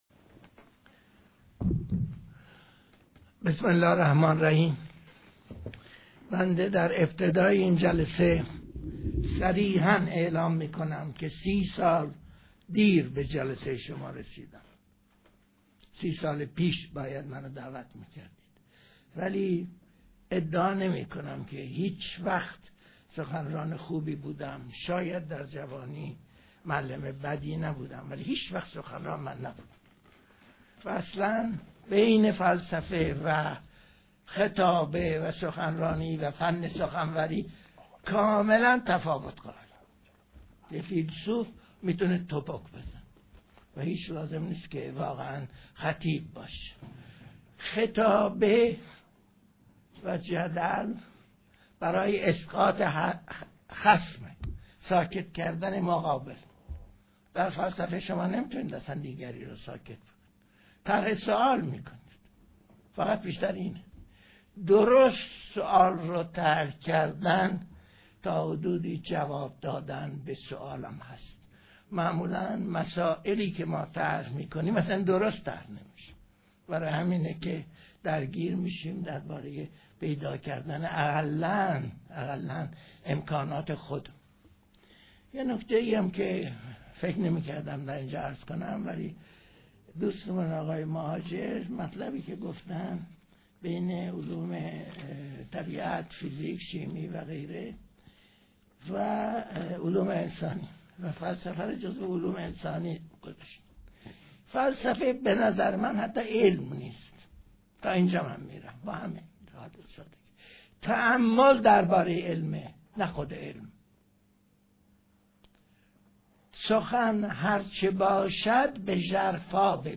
فرهنگ امروز: فایل حاضر سخنرانی «کریم مجتهدی» در همایش روز جهانی فلسفه است که در آذر ماه ۹۳ در دانشگاه صنعتیشریف برگزار شد.